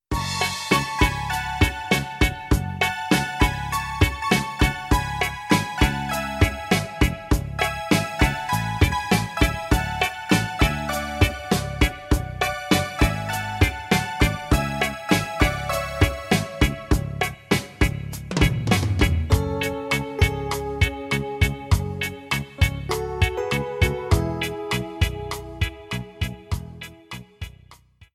73-Grupero-Balada.mp3